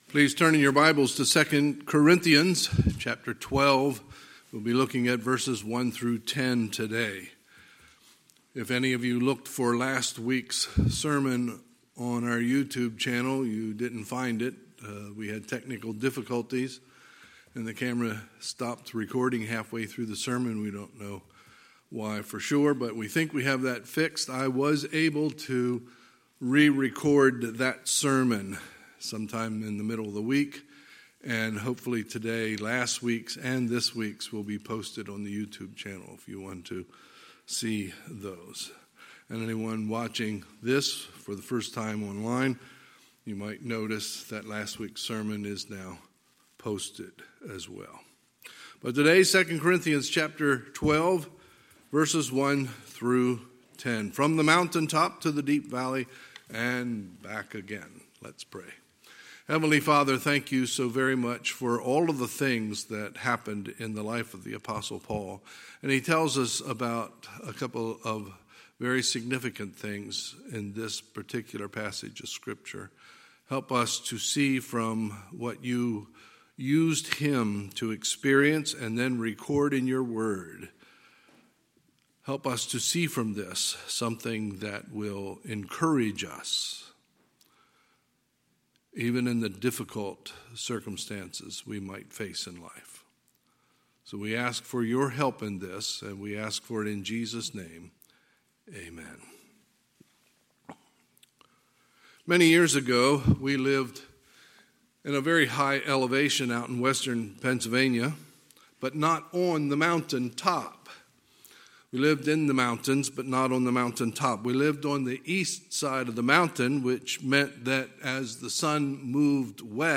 Sunday, November 1, 2020 – Sunday Morning Service